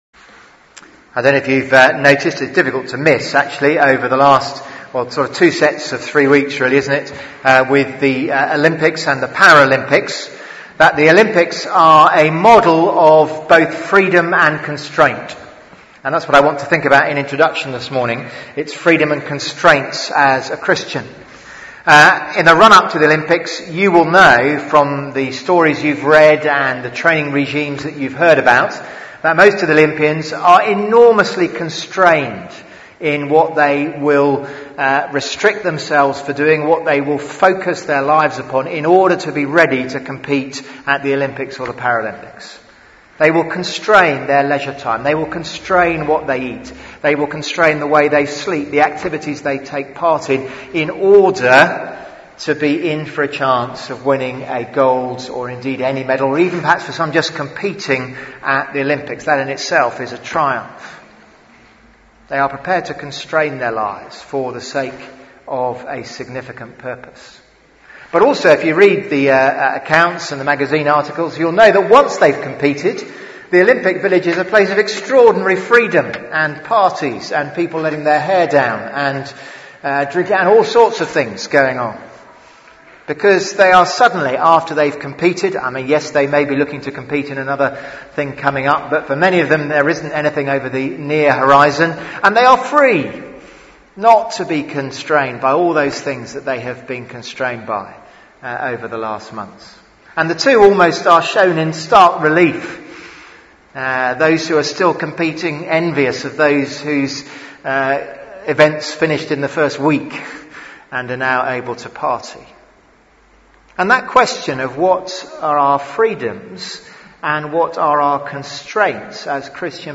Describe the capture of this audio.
11am Service on Sun 18th Sep 2016